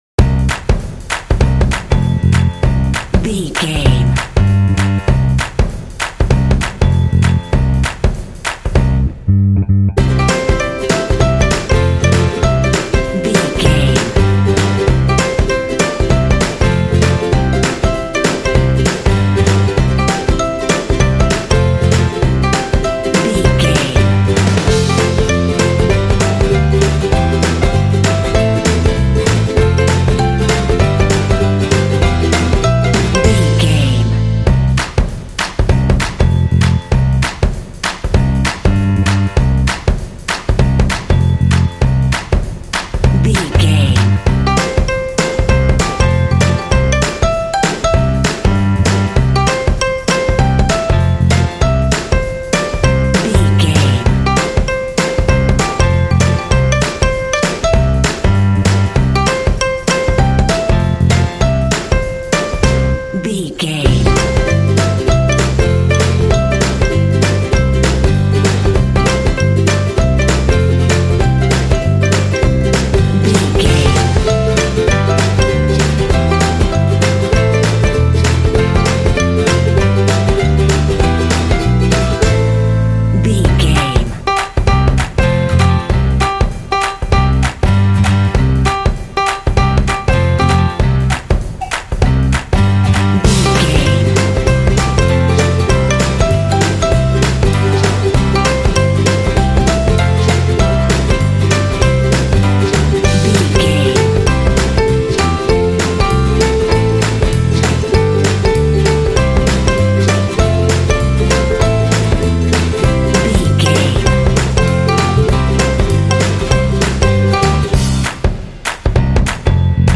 Ionian/Major
bouncy
bright
driving
energetic
festive
joyful
acoustic guitar
piano
bass guitar
drums
rock
contemporary underscore
alternative rock
indie